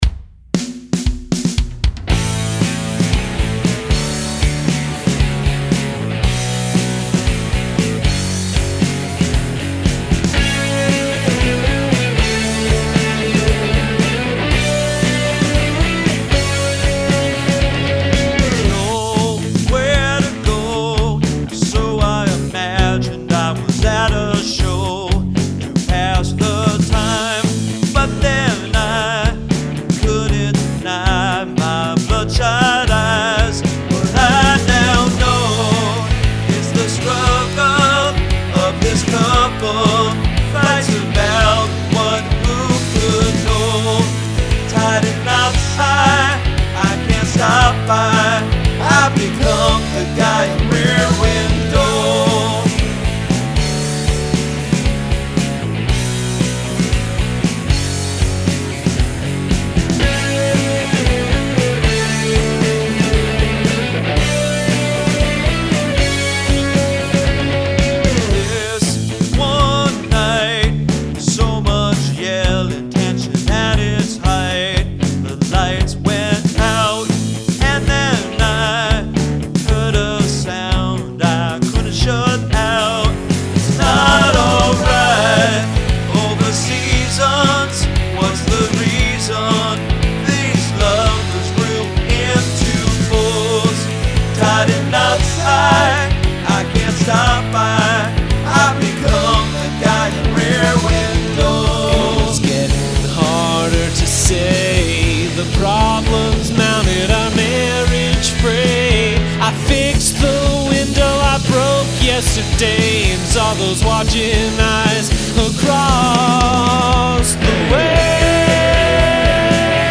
Shiny sounding, but forgettable.
DYNAMICS/MIX: [GOOD] 2 points